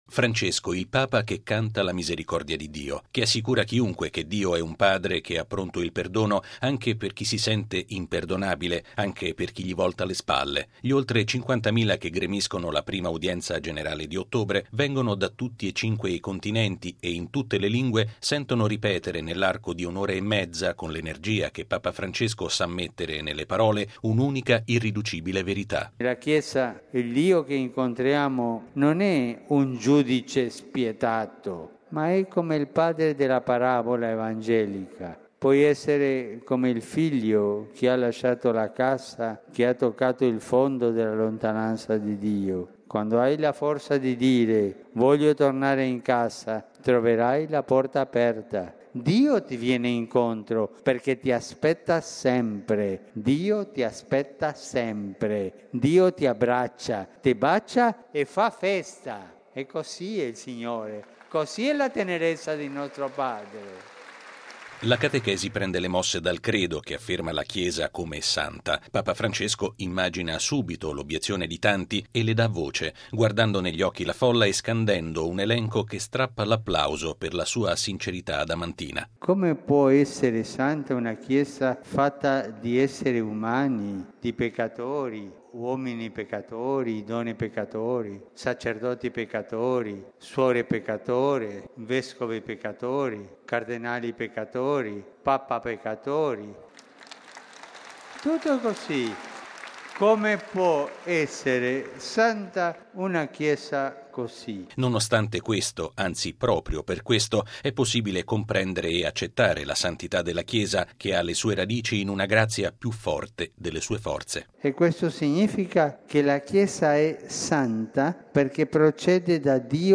Papa Francesco lo ha ripetuto questa mattina dedicando la catechesi dell’udienza generale al passaggio del “Credo” in cui si afferma la santità della Chiesa. Molti gli applausi che hanno sottolineato i passaggi più intensi sulla misericordia di Dio verso ogni persona.
Papa Francesco immagina subito l’obiezione di tanti, e le dà voce, guardando negli occhi la folla e scandendo un elenco che strappa l’applauso per la sua sincerità adamantina: